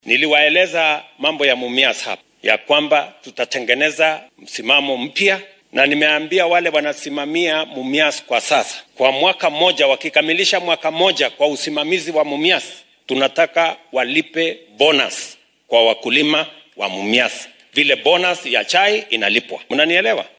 Madaxweynaha dalka William Ruto ayaa ku amray maamulka shirkadda sonkorta ee Mumias inuu bixiyo lacago gunno ah oo la siinayo beeralayda soo saaro sonkorta ee warshaddaasi. Hadalkan ayuu madaxweynaha ka sheegay ismaamulka Kakamega uu maanta uga qayb galay munaasabad kaniiseed.